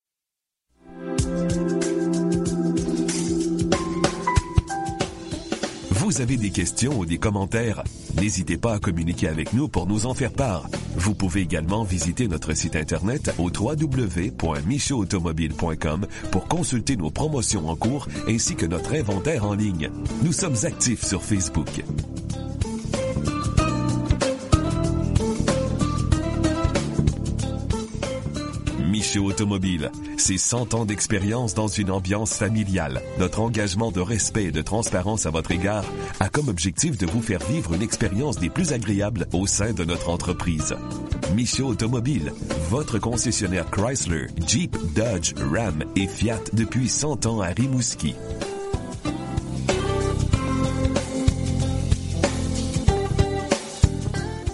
Comprend un message d’attente, la rédaction, la narration et le montage musical (pas de contrat et pas de versement mensuel)